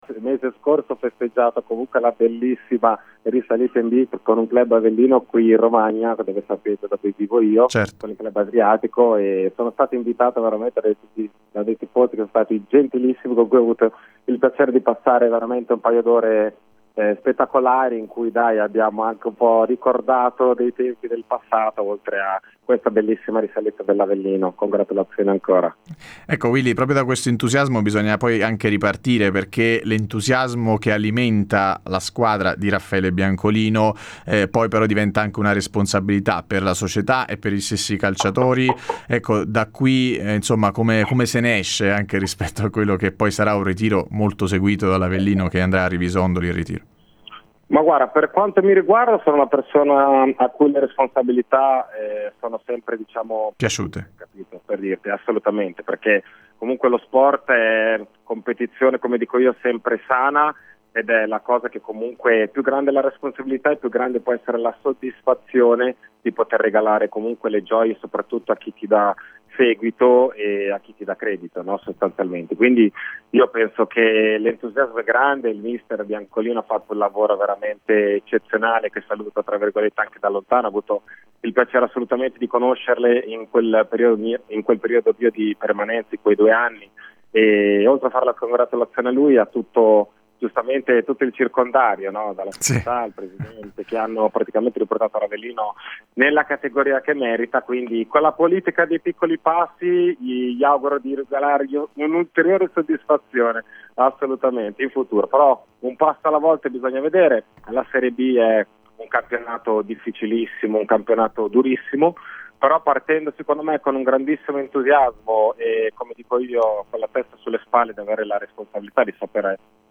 Ospite di Radio Punto Nuovo in diretta al Pomeriggio da Supereroi